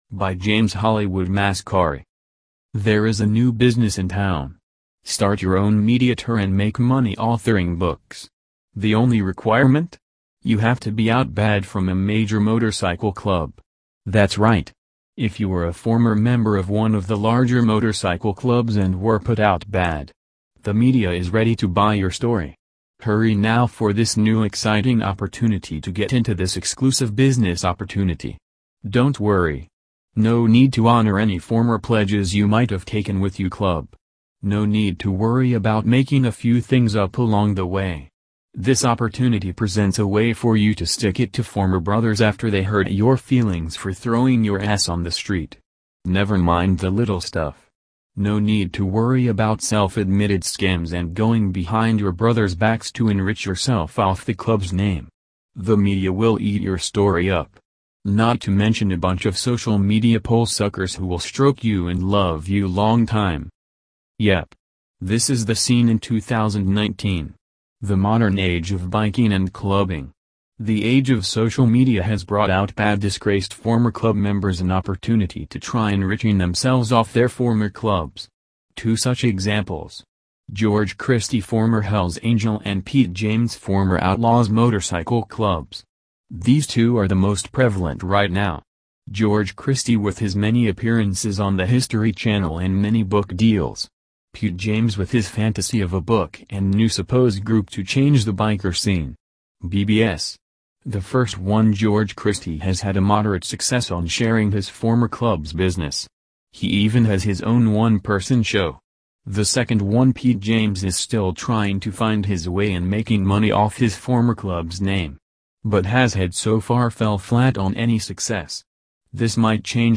Want to listen to the article instead of reading it?